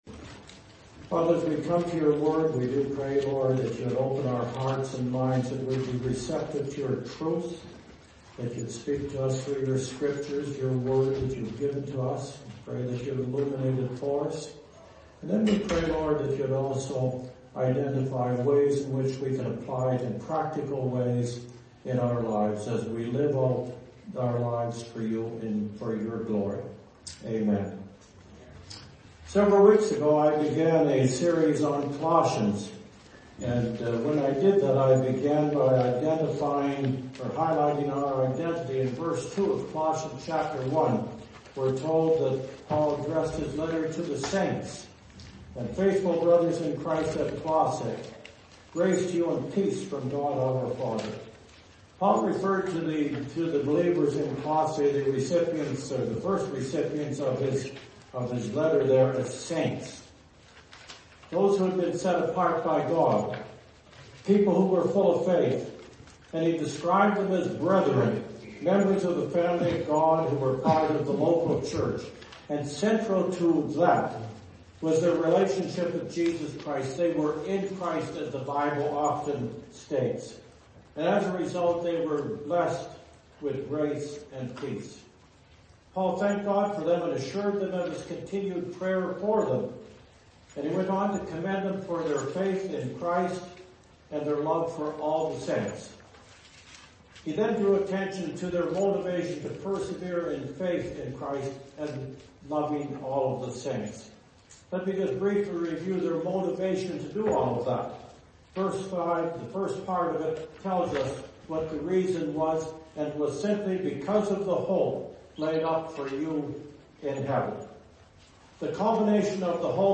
Sermons - Whiteshell Baptist Church